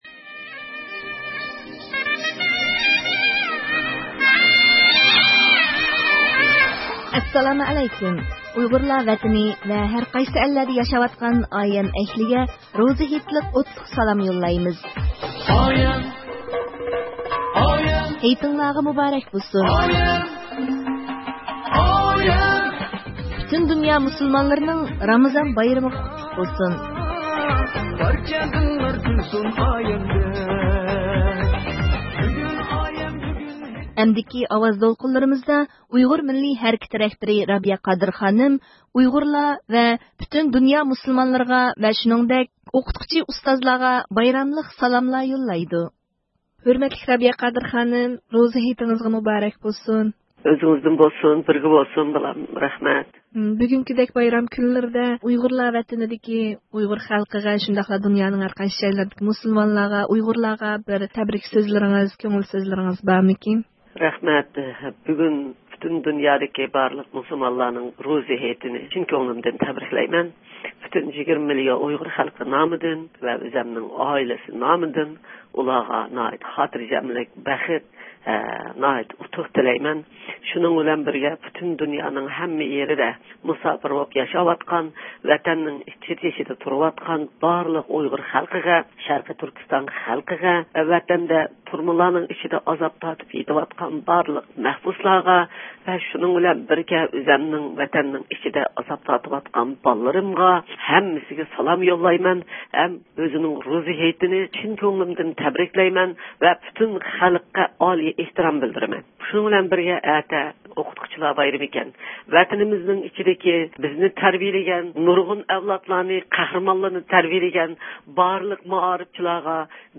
بۈگۈن يەنى 9 – سېنتەبىر كۈنى، ئامېرىكا پايتەختى ۋاشىنگىتون ۋە ئۇنىڭ ئەتراپىدا ياشايدىغان ئۇيغۇرلار بىر يەرگە جەم بولۇپ ھېيت نامىزىنى بىللە ئوقىدى ۋە شۇنداقلا بۇ ئۇلۇغ ھېيت ئايەم كۈنلىرىدە ھىجرەتتە ياشاۋاتقان ئۇيغۇرلار بىر بىرىگە غېرىبلىق ھېس قىلدۇرماسلىق ئۈچۈن روزا ھېيتلىق پائالىيەتلەردە بىرگە بولۇپ، ئۆز - ئارا بايراملىرىنى تەبرىكلەشتى.
ئۇيغۇر مىللىي ھەرىكىتى رەھبىرى رابىيە قادىر خانىم، پۈتۈن دۇنيادىكى ئۇيغۇرلارغا ۋە پۈتۈن دۇنيا مۇسۇلمانلىرىغا، شۇنىڭدەك ئوقۇتقۇچى ئۇستازلارغا ئۆزىنىڭ يۈرەكتىن چىققان تەبرىكلىرى بىلەن بايراملىق سالاملار يوللايدۇ.